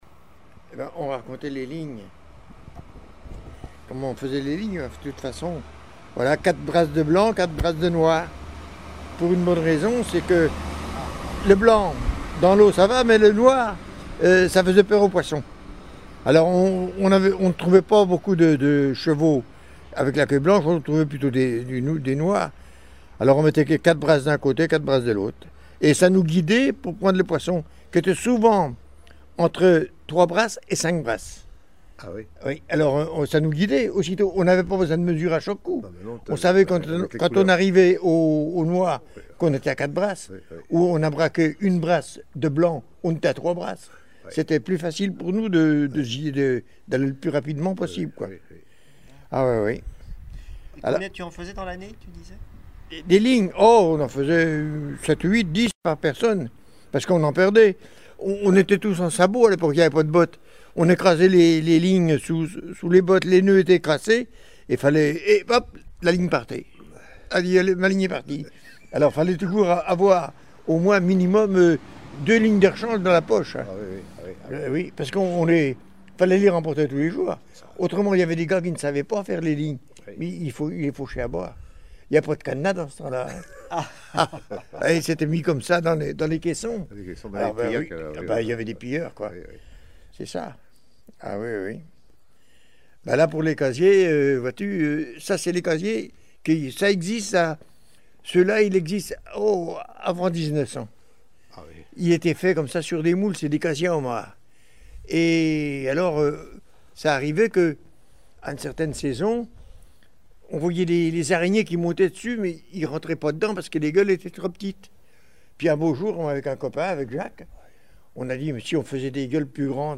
Extrait du témoignage
Témoignage oral